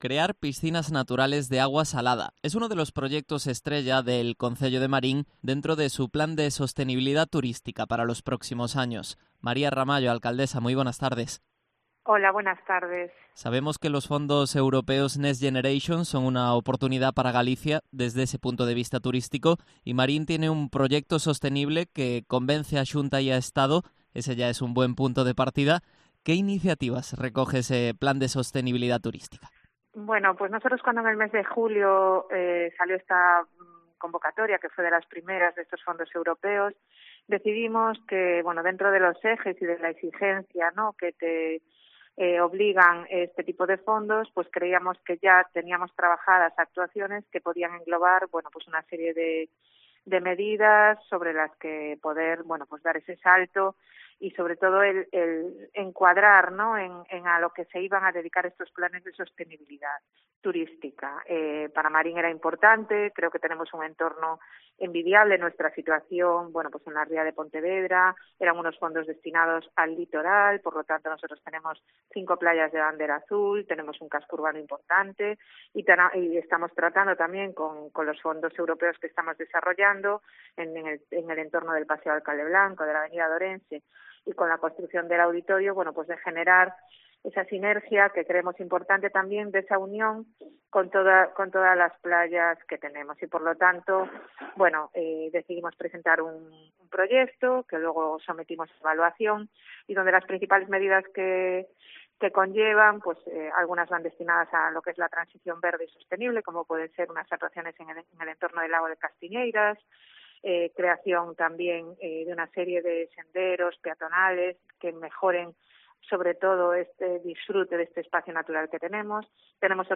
Entrevista a María Ramallo, alcaldesa de Marín